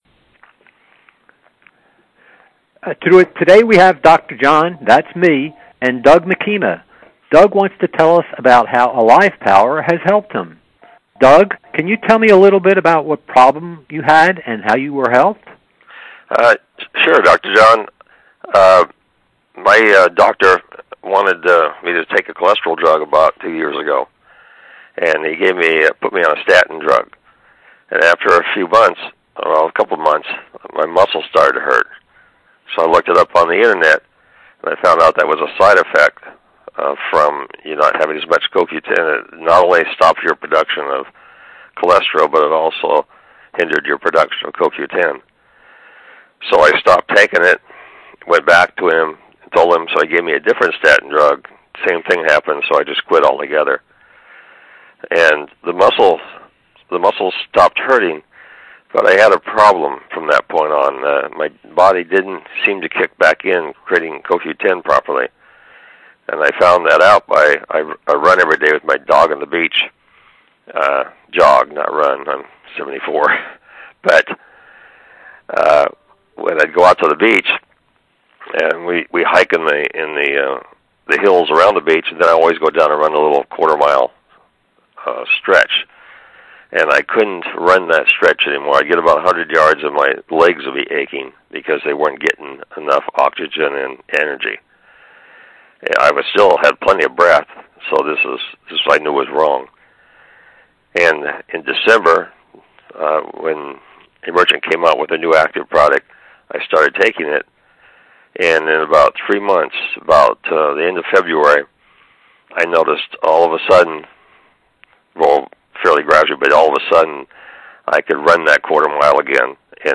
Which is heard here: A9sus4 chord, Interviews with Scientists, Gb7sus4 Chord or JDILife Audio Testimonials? JDILife Audio Testimonials